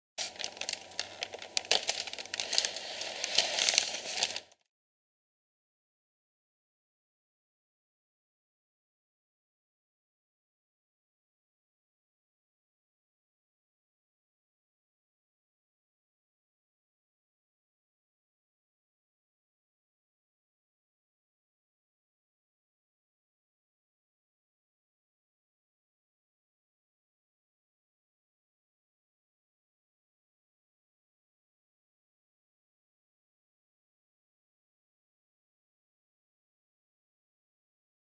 artifact_sticks.ogg